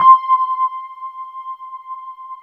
WETRD  C5 -L.wav